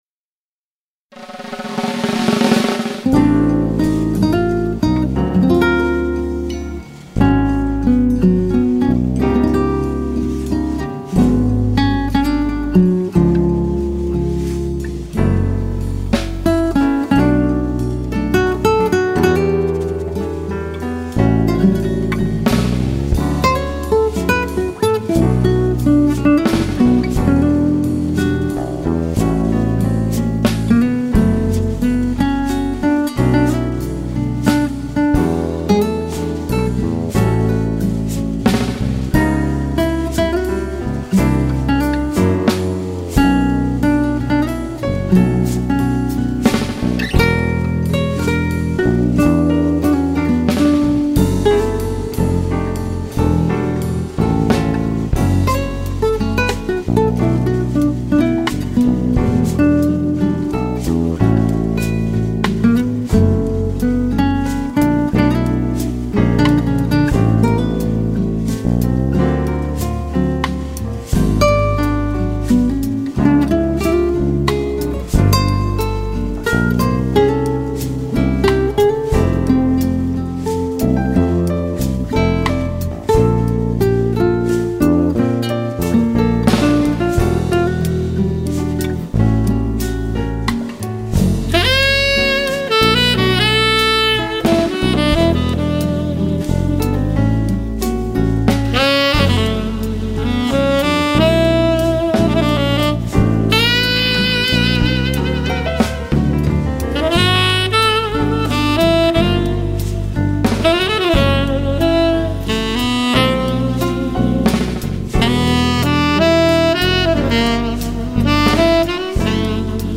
2200   05:03:00   Faixa:     Jazz
Bateria
Teclados
Baixo Acústico
Saxofone Tenor
Guitarra